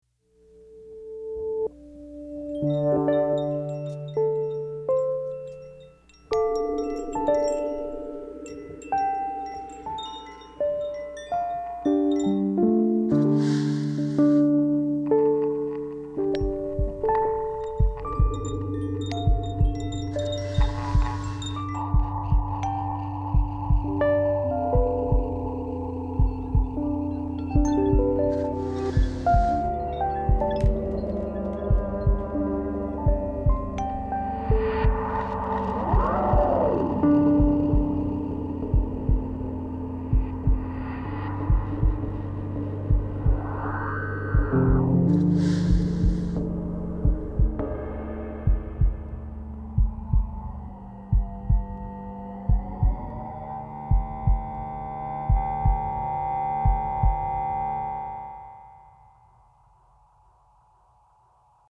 Ominous, Tragic, Drama